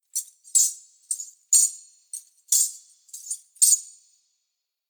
Danza árabe, bailarina mueve las tobilleras de monedas 03
agitar
Sonidos: Acciones humanas